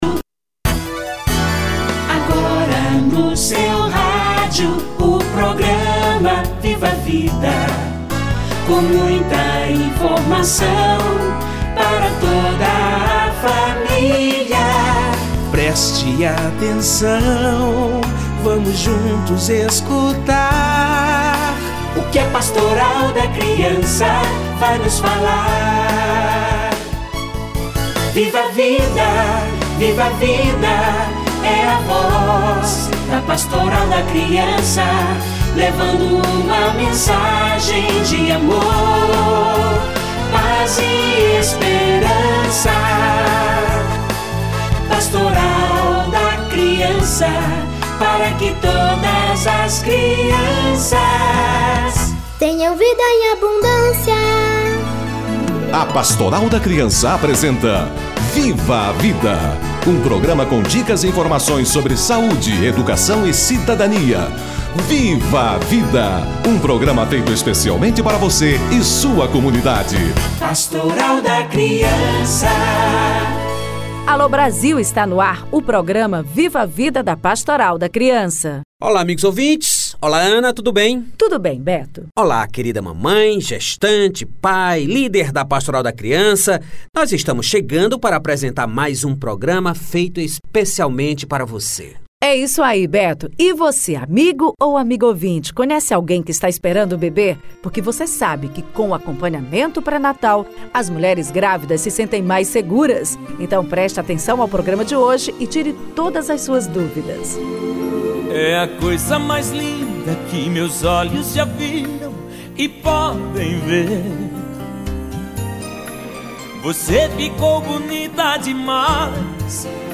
Acompanhamento na gestação - Entrevista